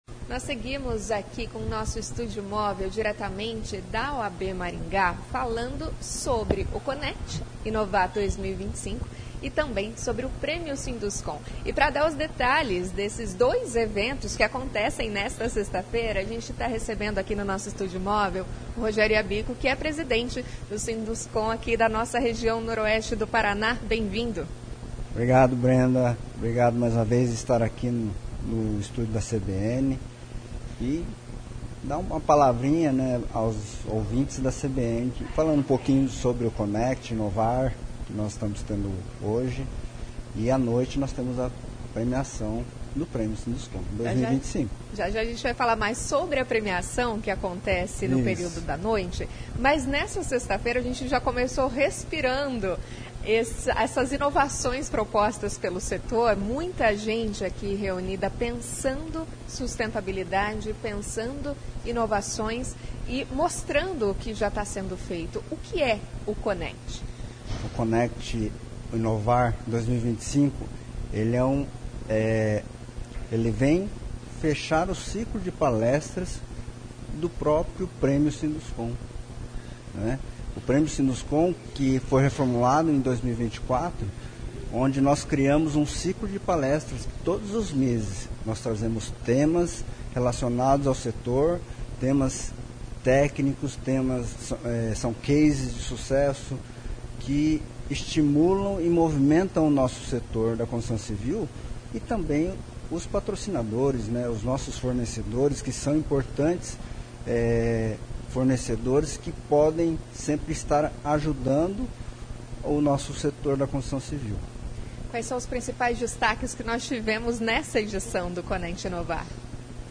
A entrevista foi realizada no Estúdio Móvel da CBN, instalado na sede da OAB Maringá, de onde ocorre a edição do Conecti, que antecede a cerimônia de entrega do Prêmio Sinduscon 2025.